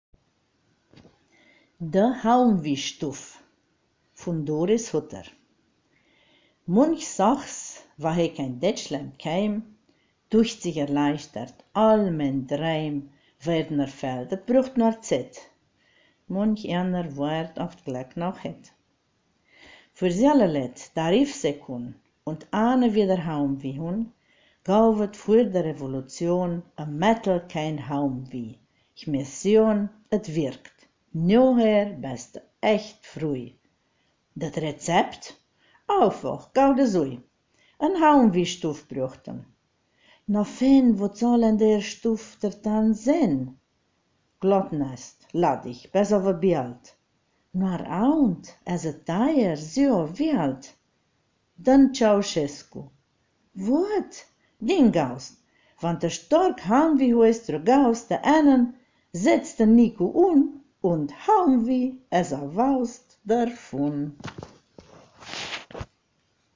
Ortsmundart: Agnetheln